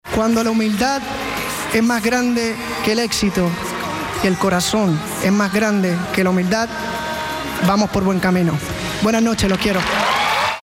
Dijo éstas palabras en los Premios Billboard
Luis-Fonsi-Mensaje-Billboard.mp3